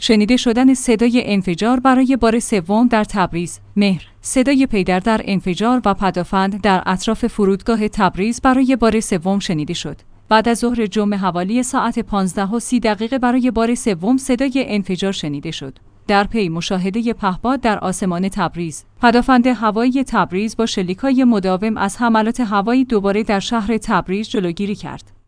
شنیده شدن صدای انفجار برای بار سوم در تبریز
مهر/ صدای پی در پی انفجار و پدافند در اطراف فرودگاه تبریز برای بار سوم شنیده شد.